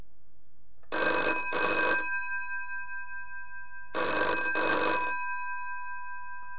ring sample.